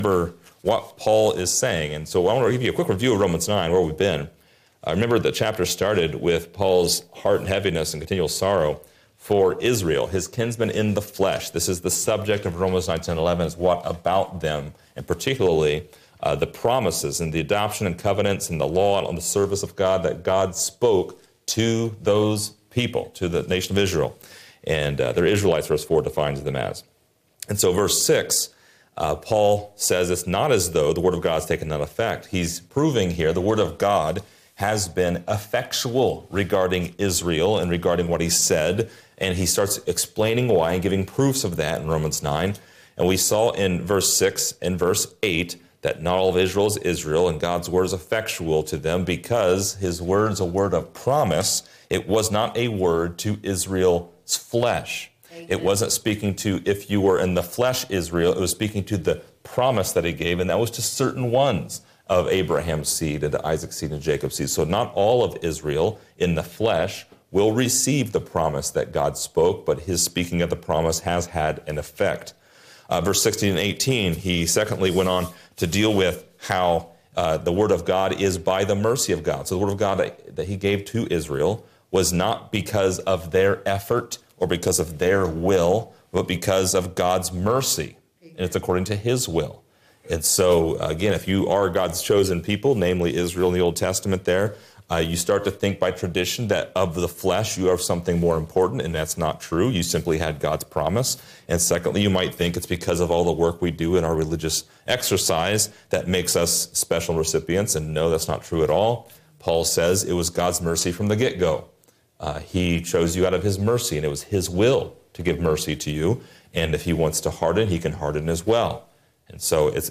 Description: This lesson is part 63 in a verse by verse study through Romans titled: The Potter and the Clay.